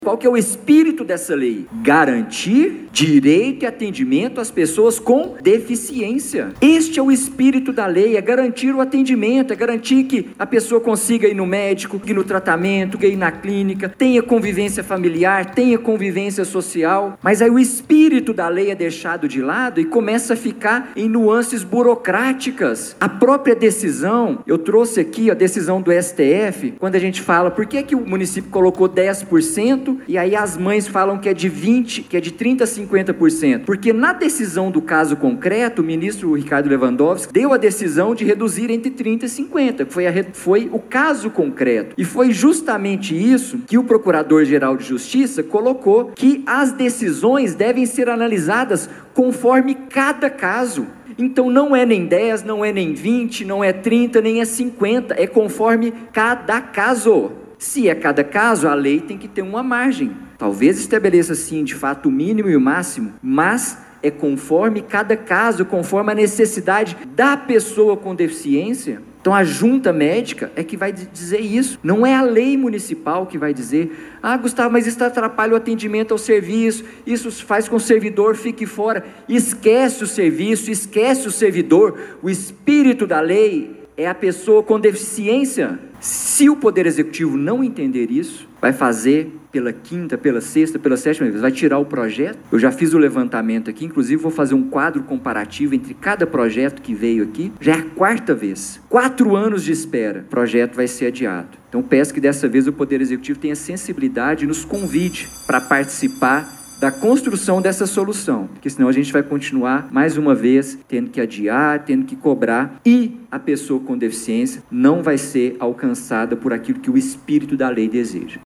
Durante o uso da tribuna, o vereador Gustavo Henrique Duarte Silva questionou a demora na resolução do impasse, que já se estende por cerca de quatro anos.